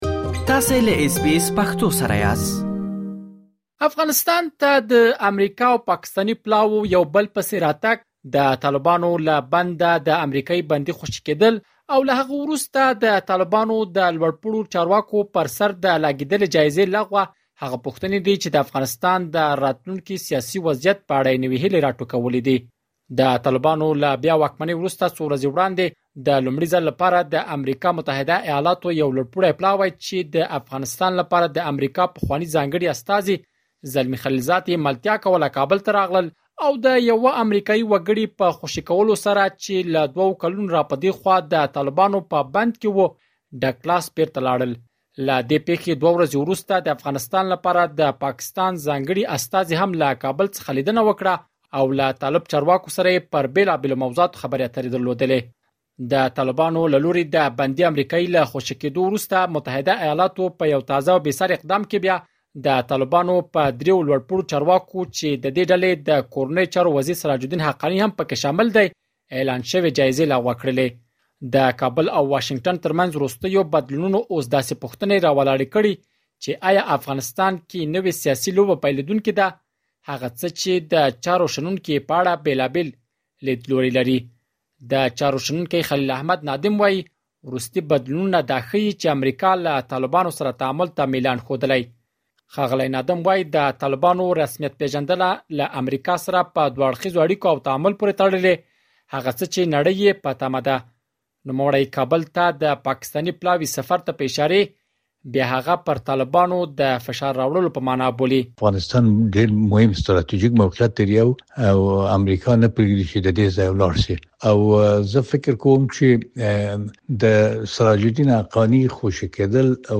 مهرباني وکړئ په دې اړه مهم معلومات په رپوټ کې واورئ.